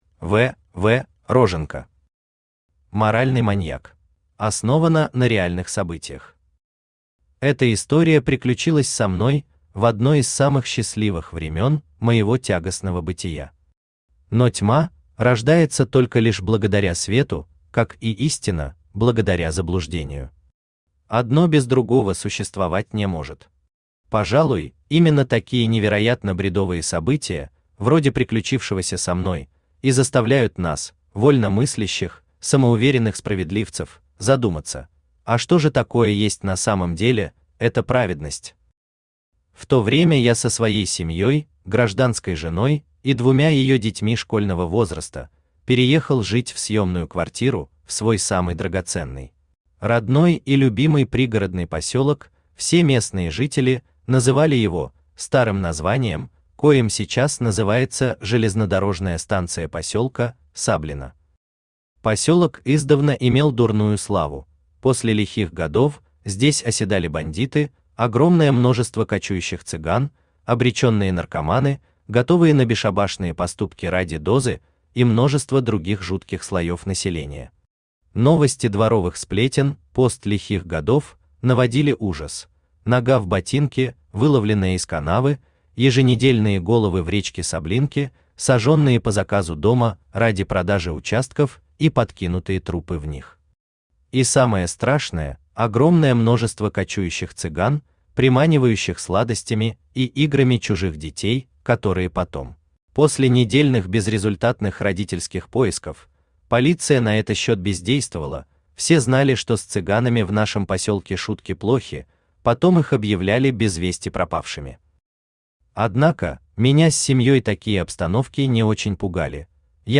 Аудиокнига Моральный маньяк | Библиотека аудиокниг
Aудиокнига Моральный маньяк Автор В. В. Роженко Читает аудиокнигу Авточтец ЛитРес.